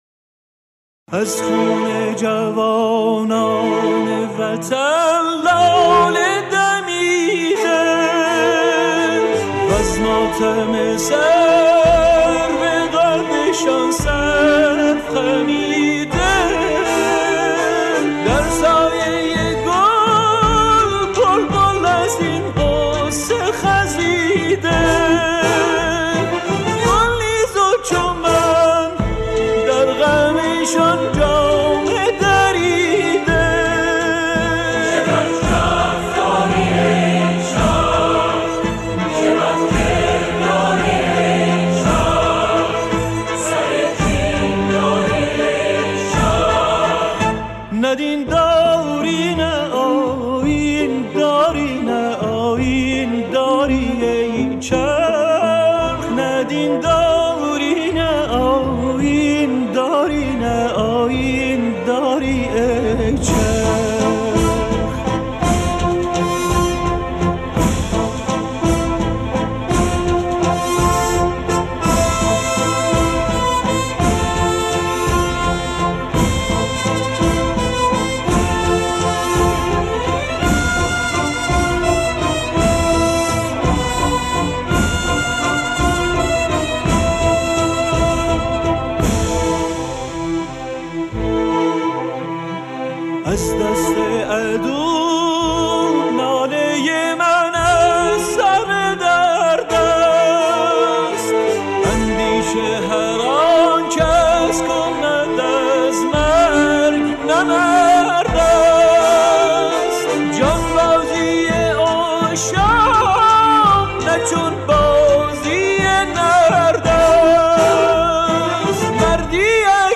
در این فهرست، تعدادی از موسیقی‌های حماسی باکلام